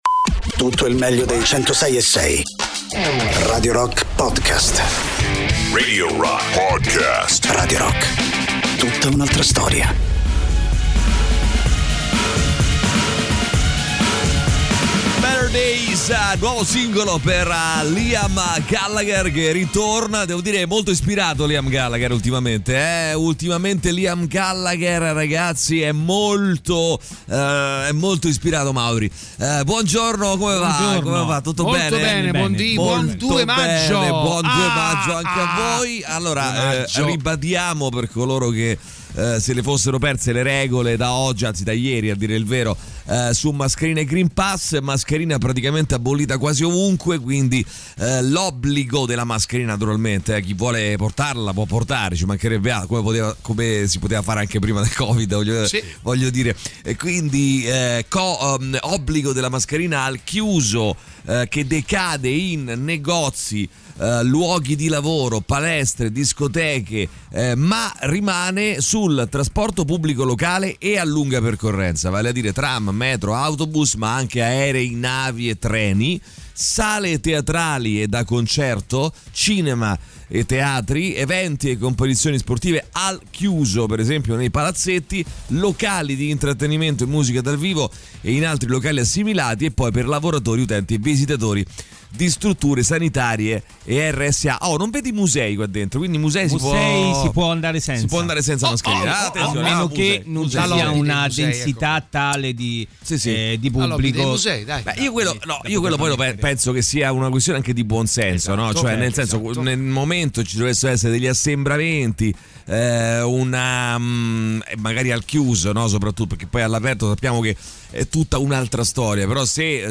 in diretta dal lunedì al venerdì dalle 6 alle 10 sui 106.6 di Radio Rock